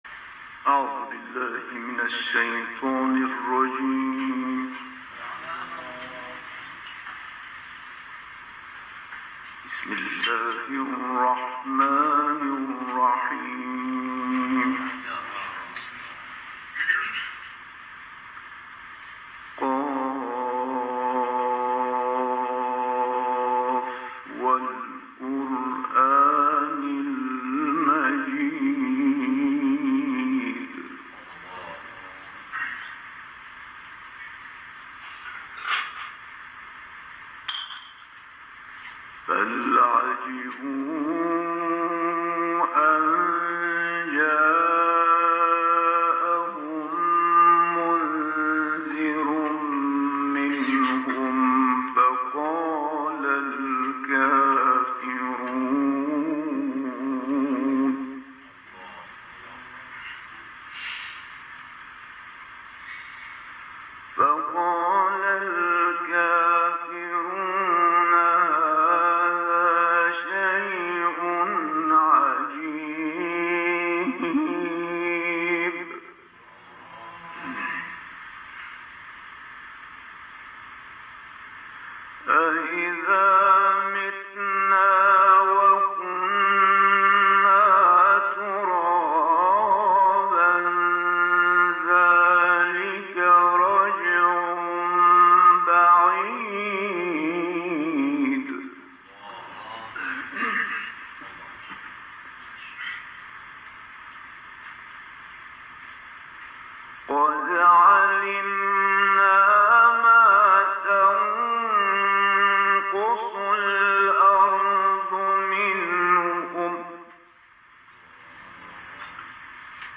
صوت/ تلاوت آیاتی از کلام‌الله مجید